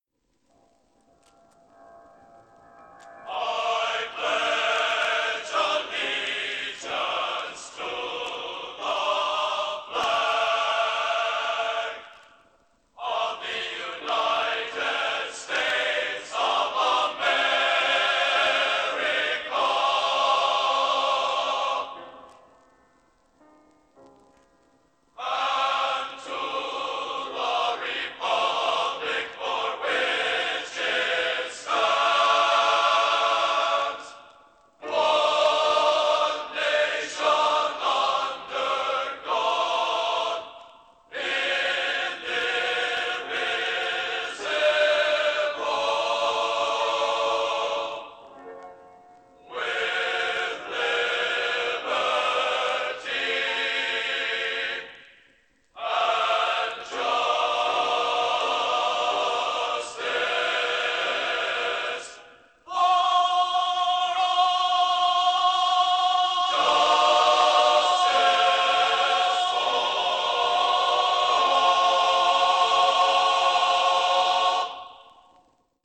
Genre: Popular / Standards | Type: Studio Recording